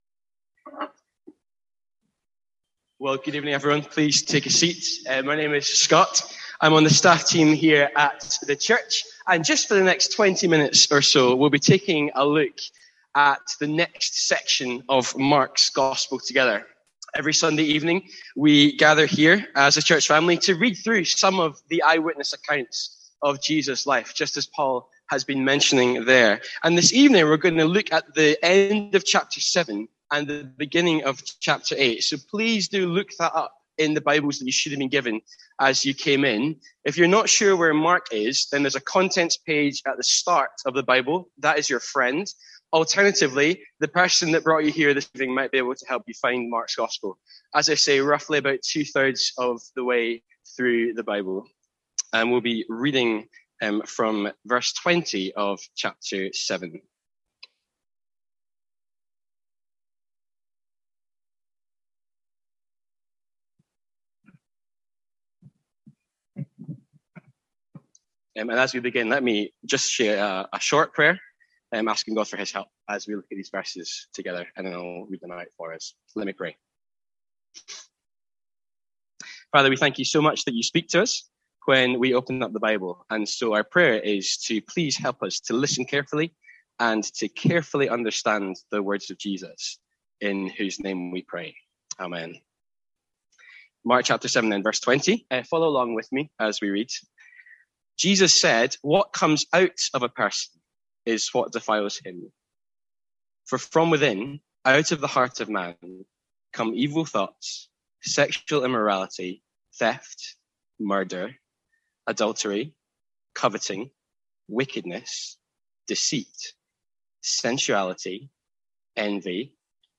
Sermons | St Andrews Free Church
From our evening series in Mark.